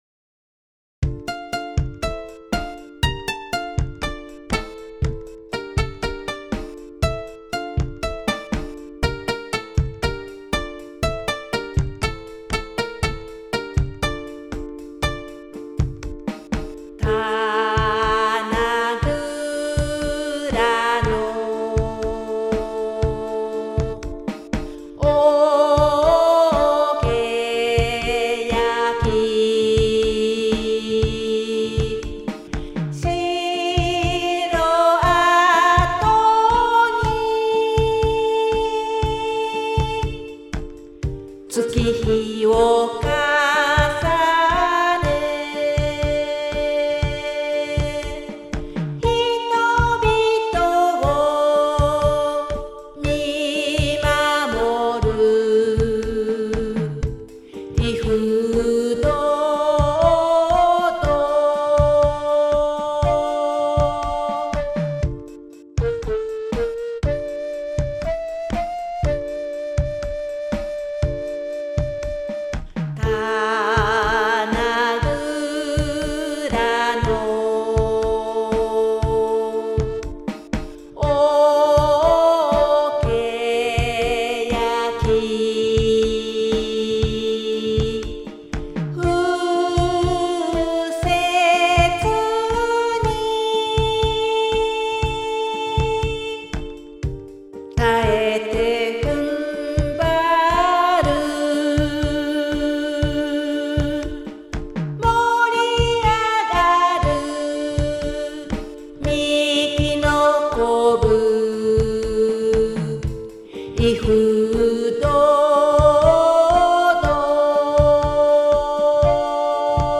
こちらは　最初に作ってみた「民謡風」のものです。
尺八風の音はDTMの音で
電子ドラム　+　テーブルを叩いています。
ookeyaki-sisaku-Dm.mp3